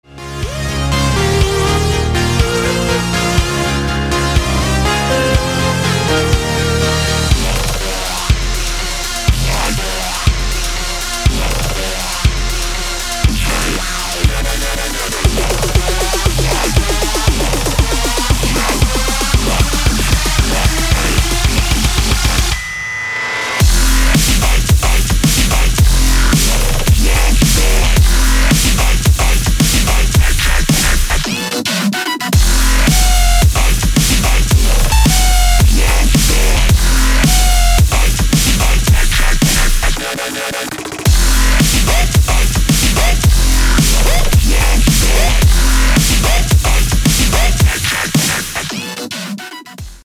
громкие
жесткие
мощные
dance
Electronic
EDM
электронная музыка
без слов
Grime